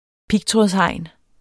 Udtale [ ˈpigˌtʁɔˀðs- ]